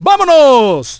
el_primo_start_vo_06.wav